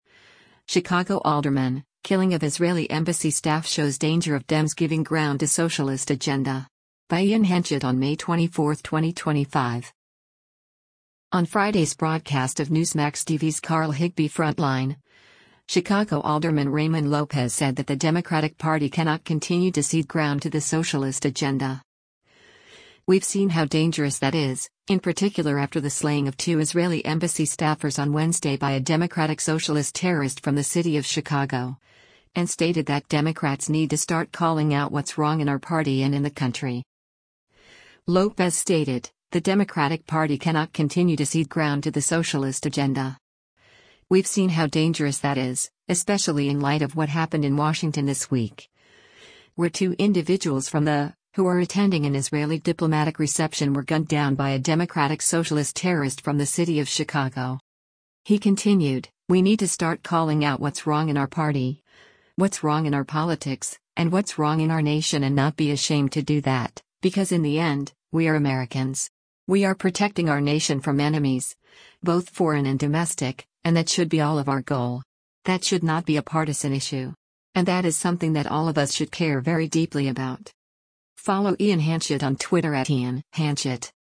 On Friday’s broadcast of Newsmax TV’s “Carl Higbie Frontline,” Chicago Alderman Raymond Lopez said that “The Democratic Party cannot continue to cede ground to the socialist agenda. We’ve seen how dangerous that is,” in particular after the slaying of two Israeli Embassy staffers on Wednesday “by a democratic socialist terrorist from the city of Chicago.”